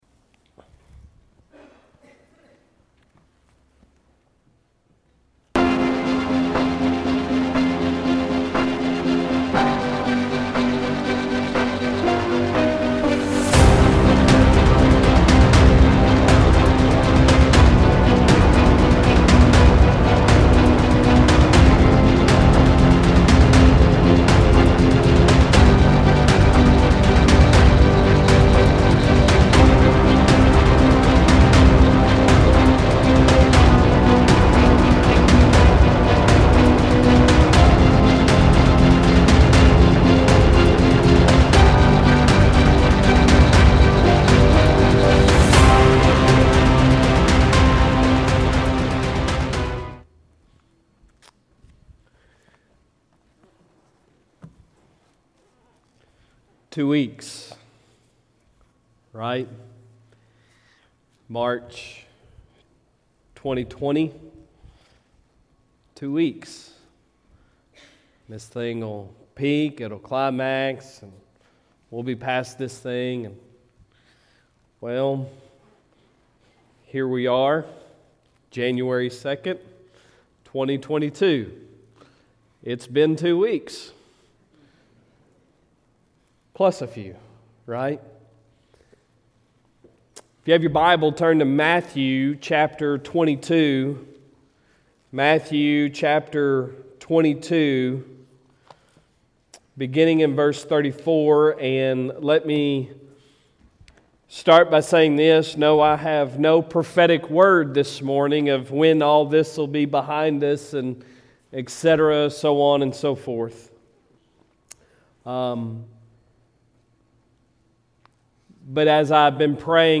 Sunday Sermon January 2, 2022